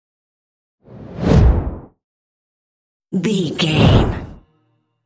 Whoosh deep fast x2
Sound Effects
Fast
dark
intense
whoosh